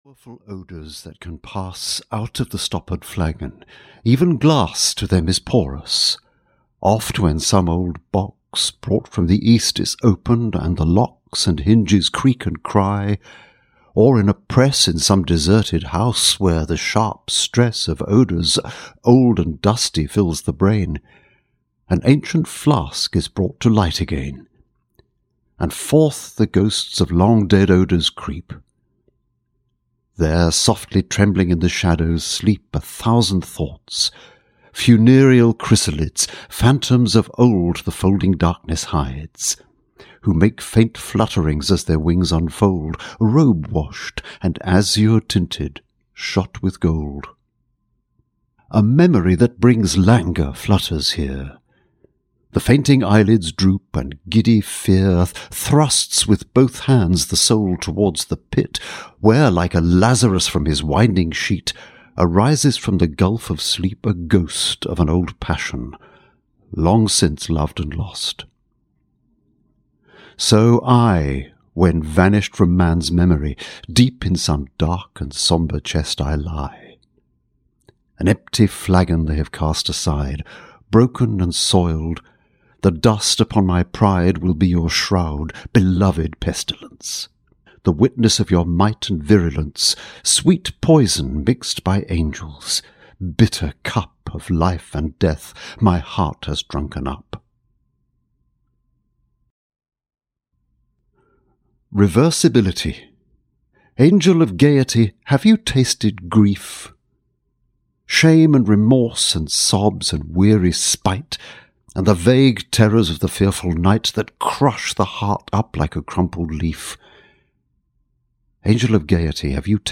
Audio kniha49 Poems from The Flowers of Evil by Baudelaire (EN)
Ukázka z knihy